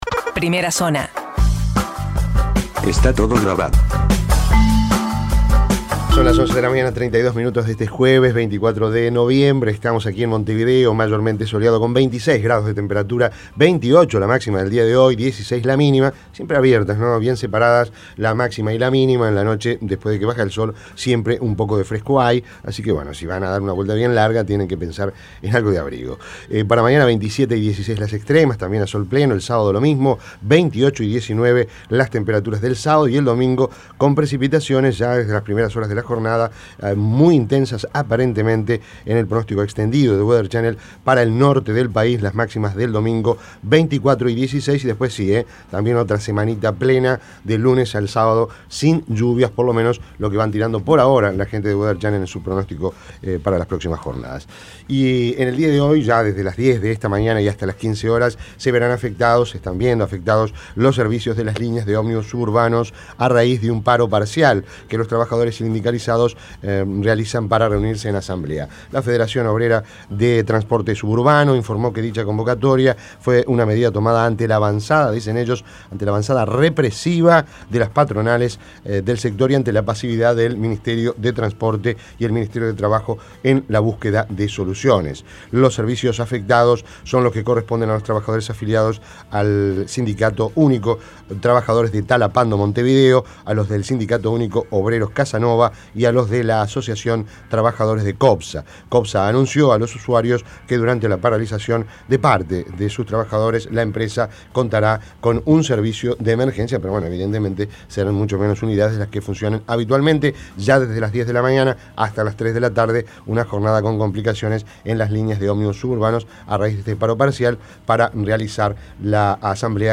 Las principales noticias del día, resumidas en la Primera Zona de Rompkbzas.
En diálogo con Rompkbzas desde Perú, Petit dijo que el sistema debe ser reformado .